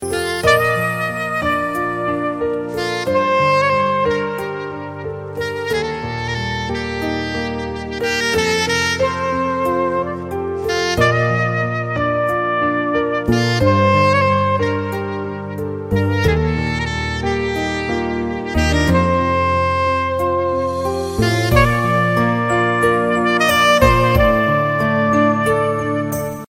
Blues Ringtones
Saxaphone